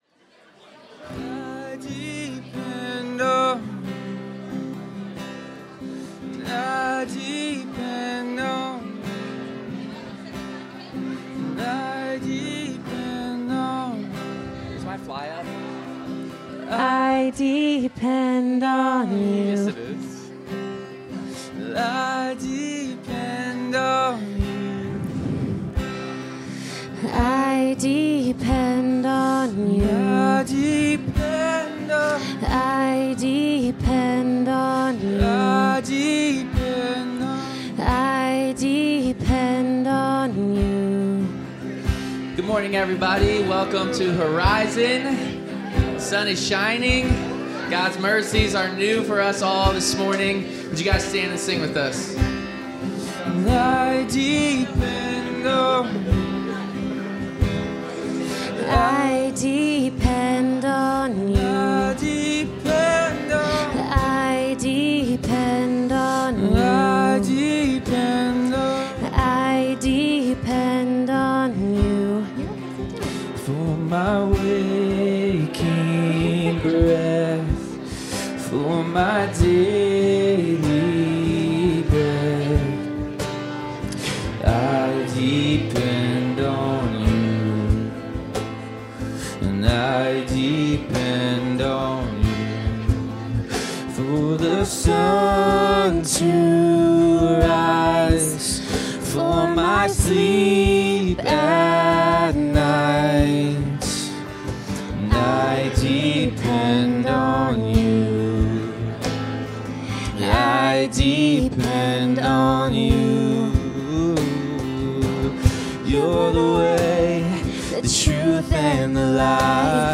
Worship 2025-03-23